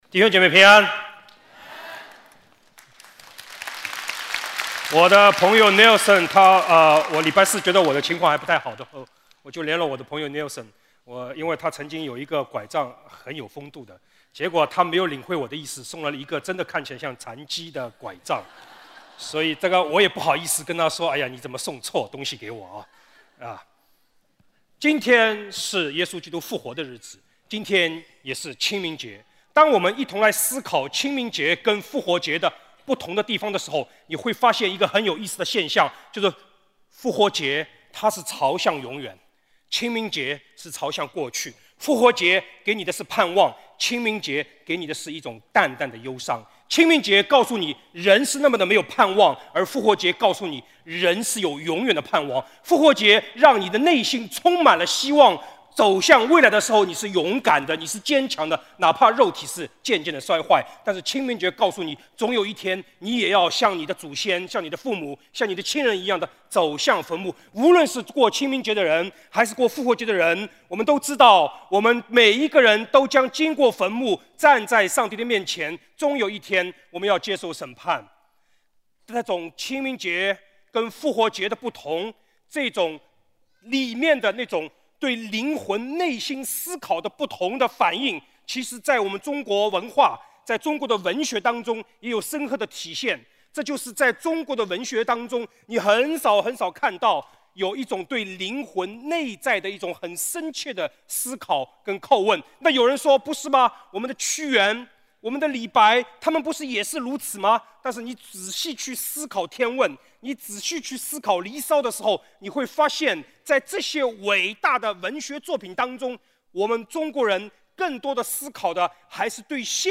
主日证道 |  复活之路